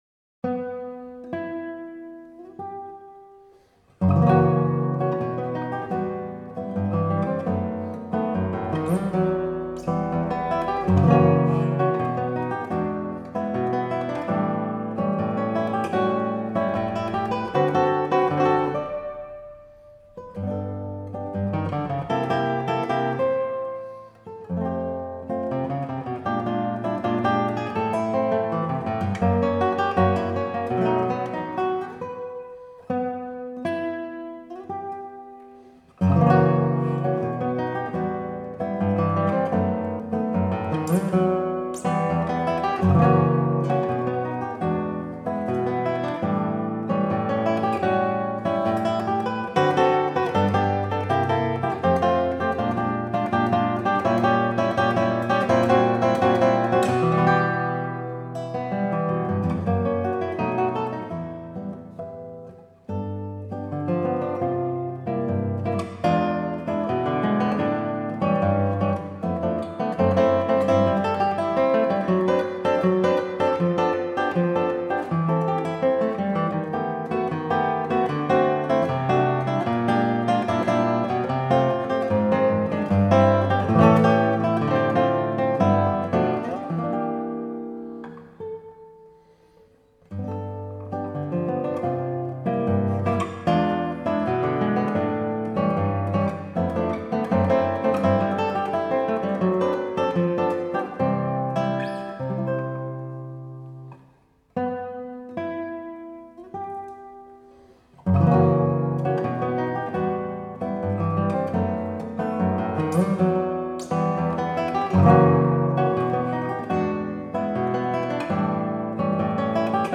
Choros N°1 para guitarra